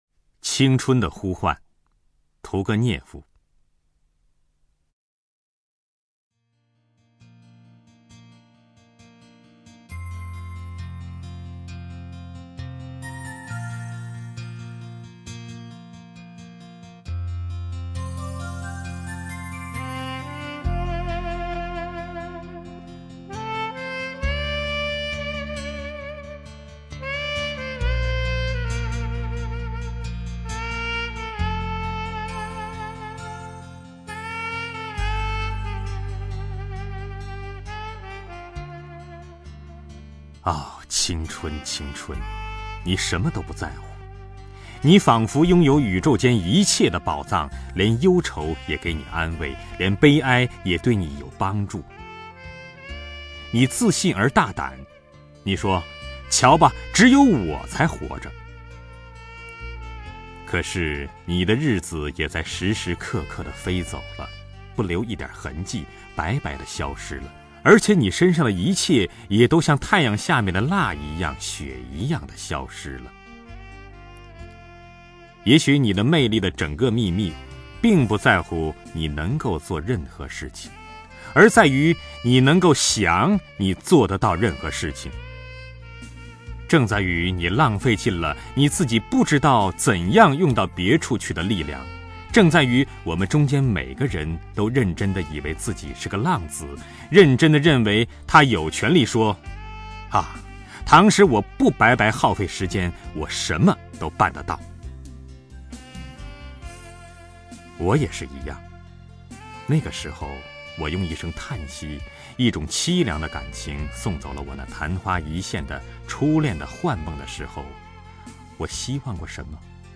张政朗诵：《青春的呼唤》(（俄）伊凡·谢尔盖耶维奇·屠格涅夫)
名家朗诵欣赏 张政 目录